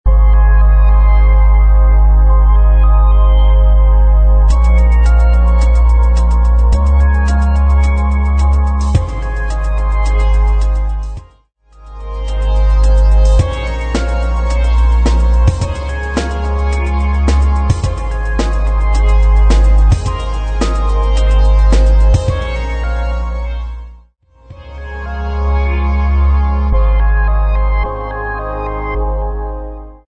108 BPM
Gentle